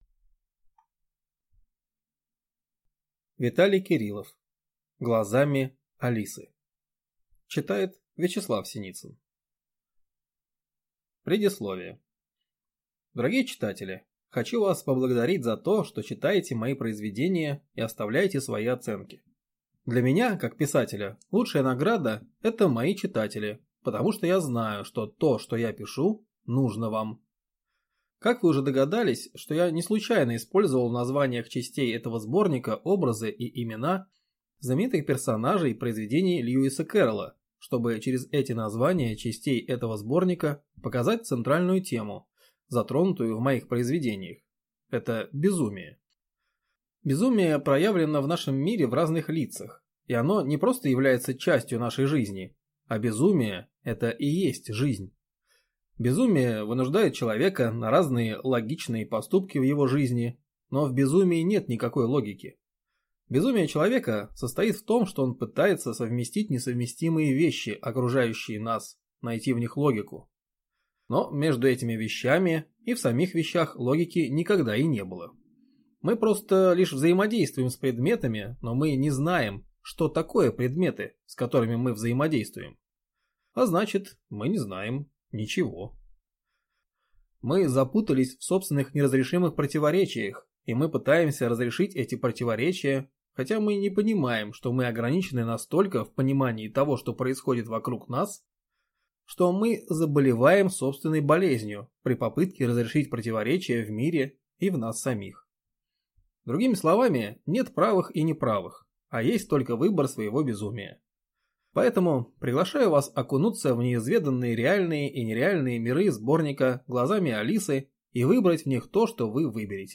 Аудиокнига Глазами Алисы. Сборник | Библиотека аудиокниг